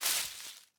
Minecraft Version Minecraft Version snapshot Latest Release | Latest Snapshot snapshot / assets / minecraft / sounds / block / leaf_litter / step2.ogg Compare With Compare With Latest Release | Latest Snapshot